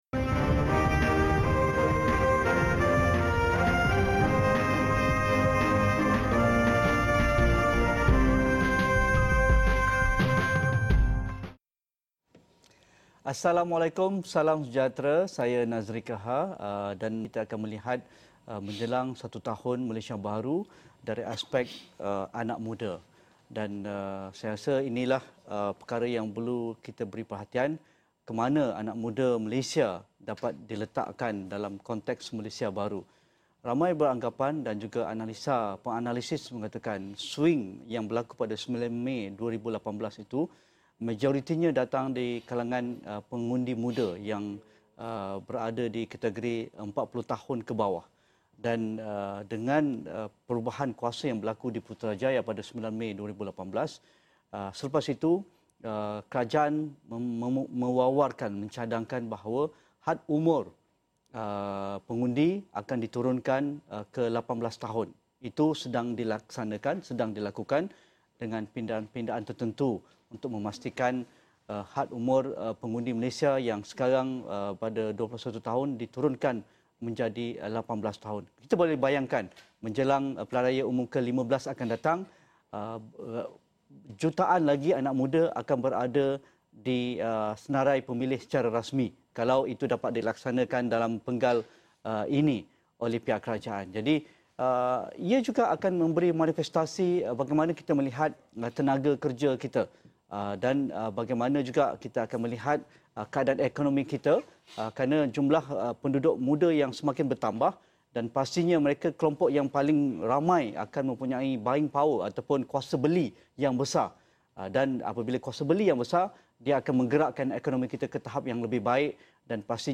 Timbalan Menteri Belia & Sukan, Steven Sim bercakap mengenai refleksi setahun pentadbiran Pakatan Harapan dari sudut pandang Kementerian Belia dan Sukan di dalam program Menjelang Setahun Malaysia Baharu.